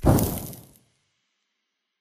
Sound / Minecraft / mob / zombie / infect.ogg
infect.ogg